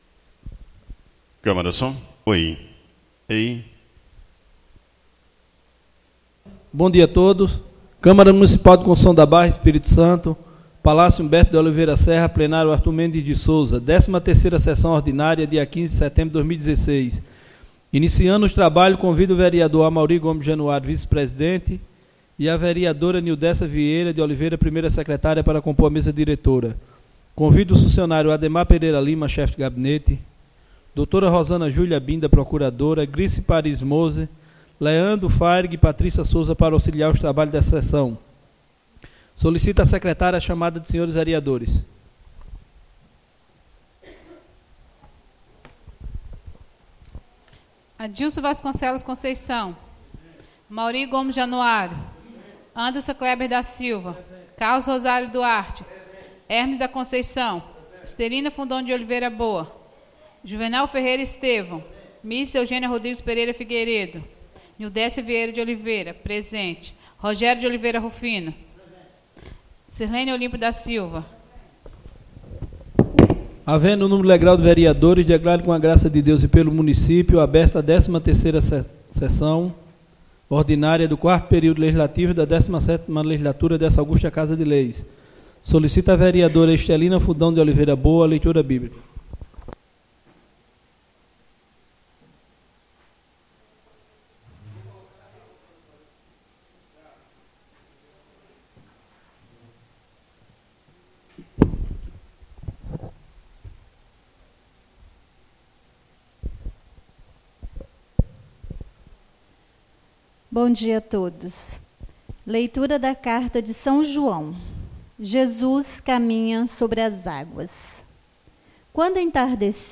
13 Sessão Ordinária 15 de set 2016 sede
13 Sessão Ordinária 15 de set 2016